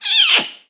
Звук самки орангутана при одиночном крике